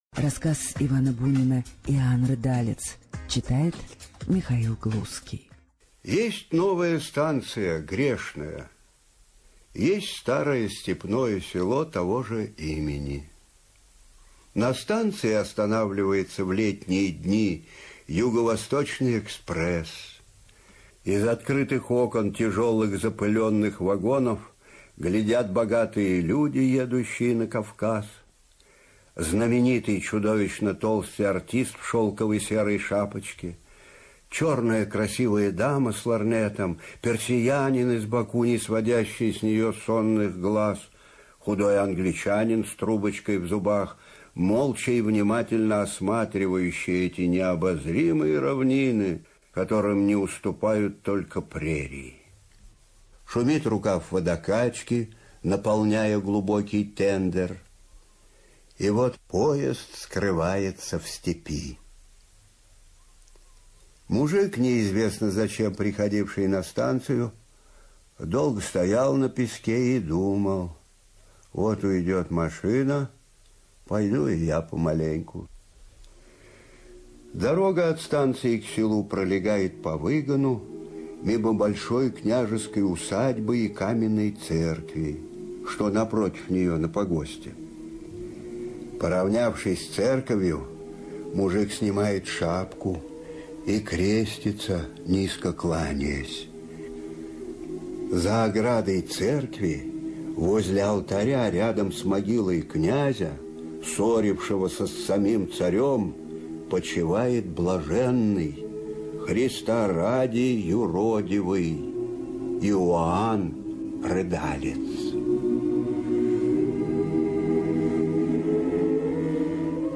ЧитаетГлузский М.
Бунин И - Иоанн Рыдалец (Глузский М.)(preview).mp3